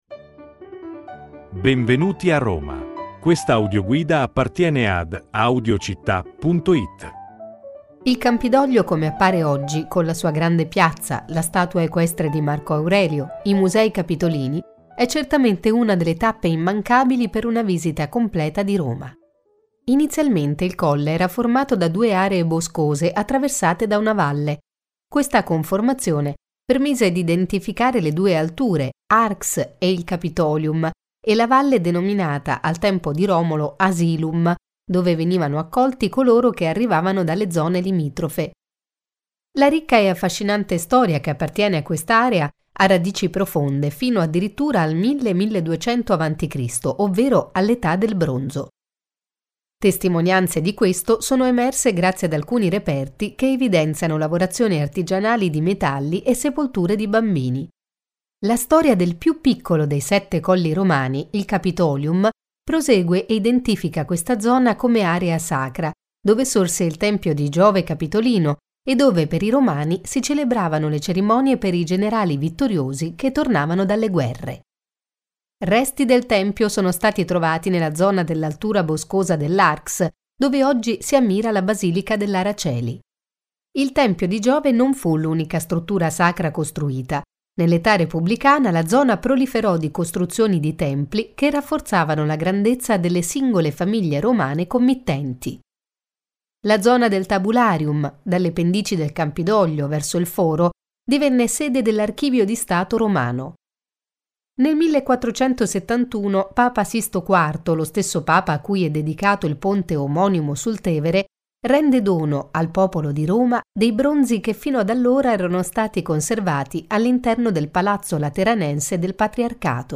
Audioguida Roma – Il Campidoglio